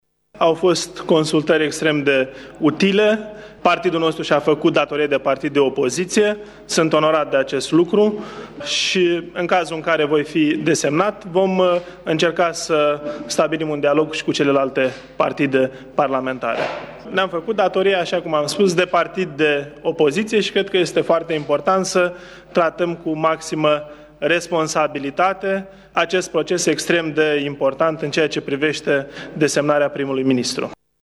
Eugen Tomac, propunerea PMP pentru funcția de premier din partea opoziției, este onorat de încrederea acordată de colegii săi: